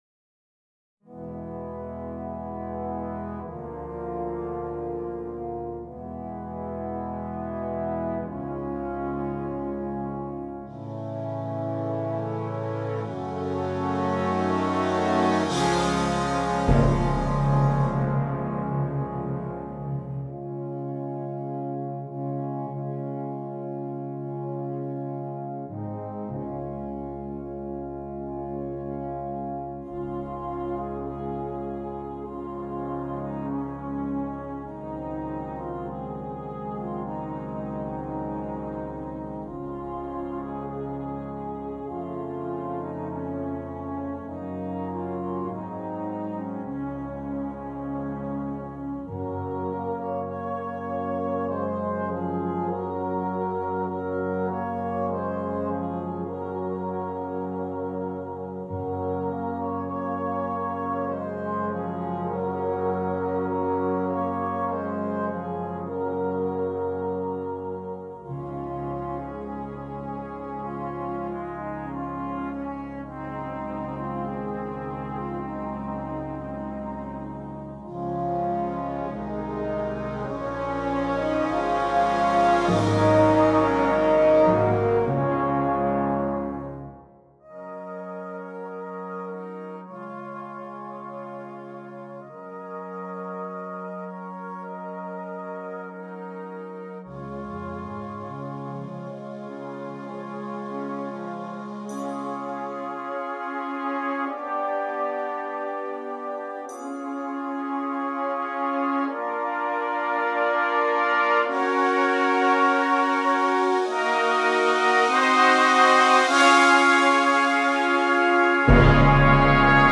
Brass Choir